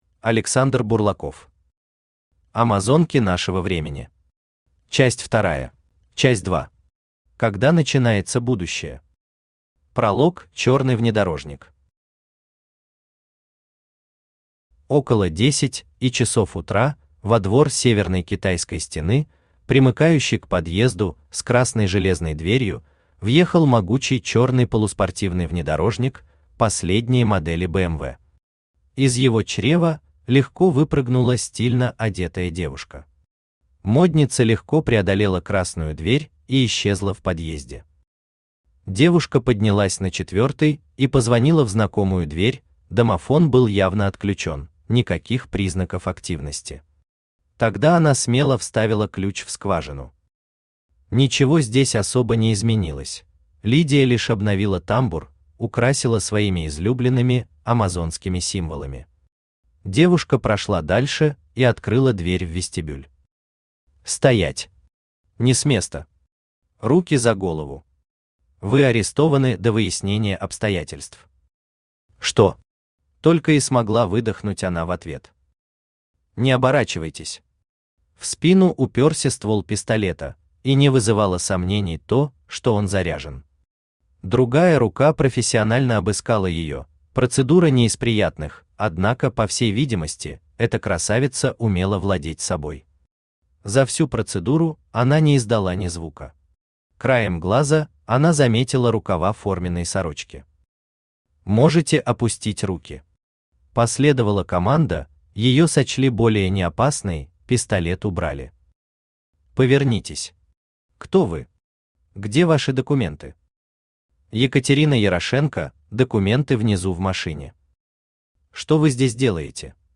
Часть Вторая Автор Александр Бурлаков Читает аудиокнигу Авточтец ЛитРес.